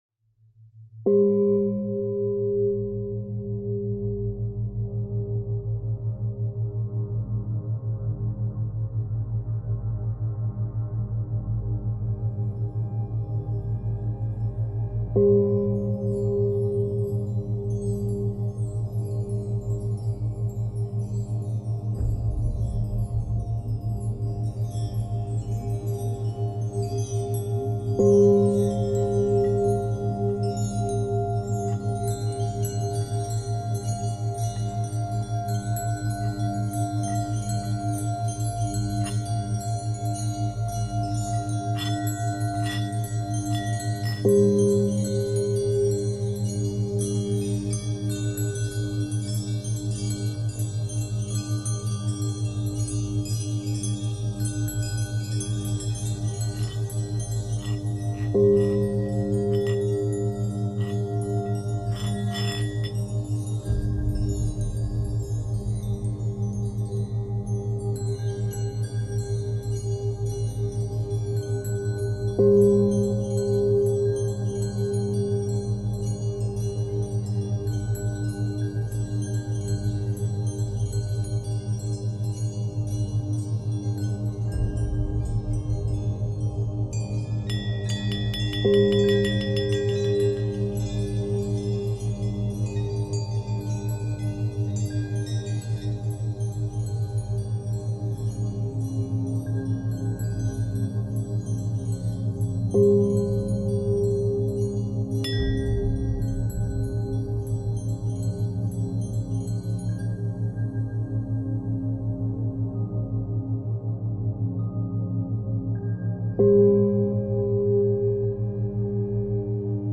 La méditation au bol tibétain facilite l’harmonisation de l’être
CHANTS TIBÉTAINS
bol-tibetain-q-guerit-et-nettoie-profondement-lharmonisation-de-letre.mp3